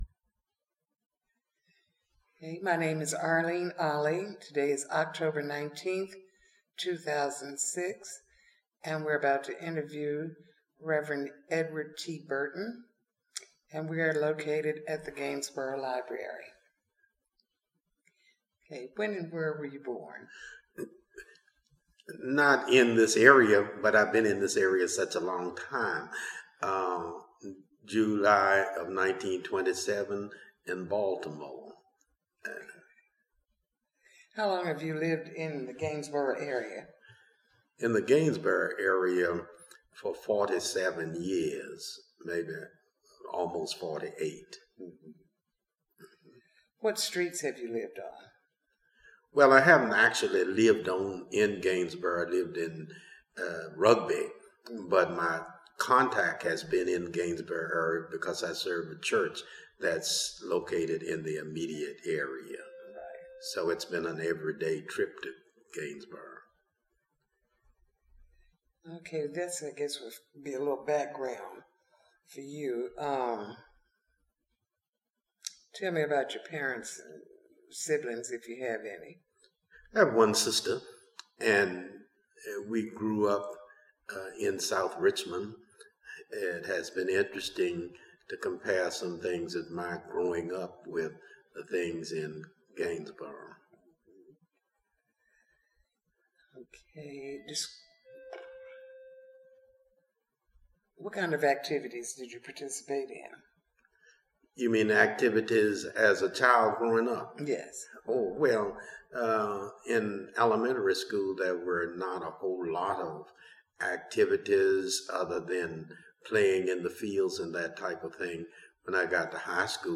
Neighborhood History Interview
Location: Gainsboro Branch Library